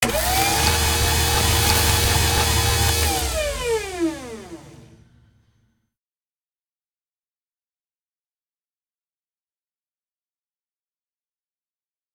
recharge.ogg